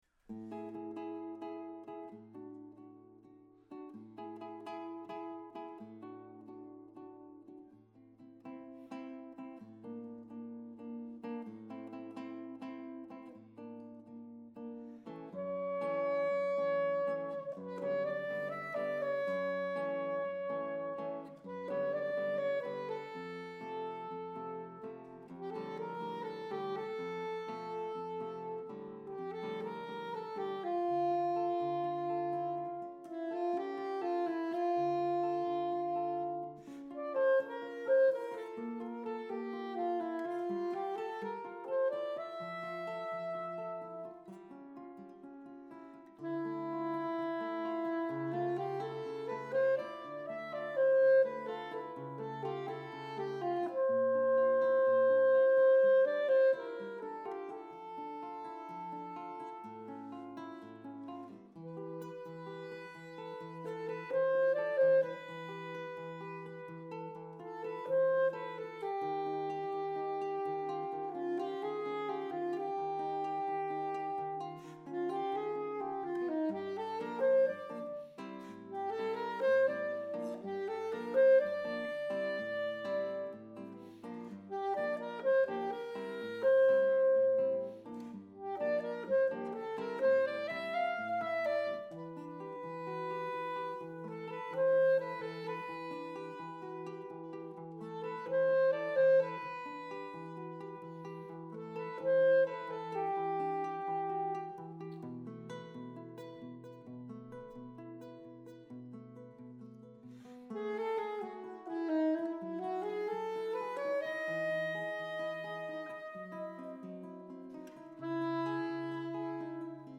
for guitar & soprano sax